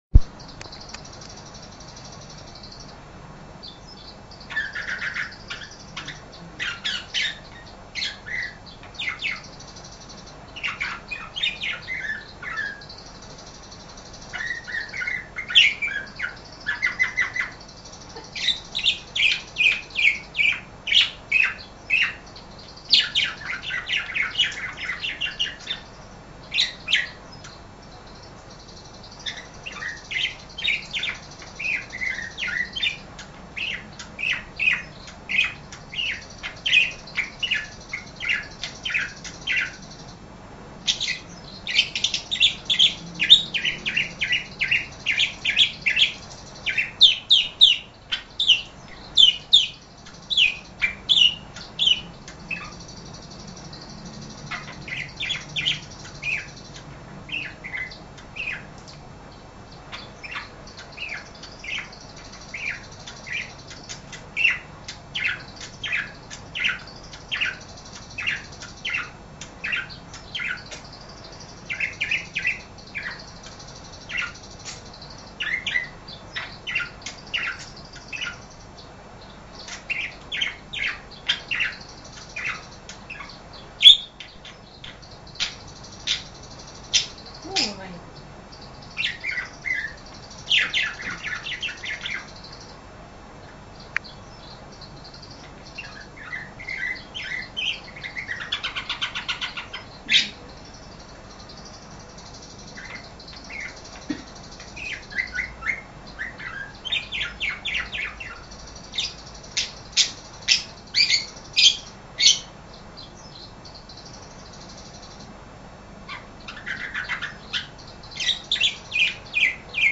Mp3 Suara Burung Parkit Free (Gratis)
Burung parkit terkenal akan suara ngoceh ngekek merdu, ngriwik dan nembak nyecret.
Suara cerecetan parkit nyaris seperti suara burung gereja tarung dan keras ocehan dari parkit seperti suara lovebird, suara ngeriwik dari burung perkit sendiri bisa dijadikan bahan untuk pemikat atau memancing kumpulan maupun rombongan asli alam liar dari burung warna warni ini.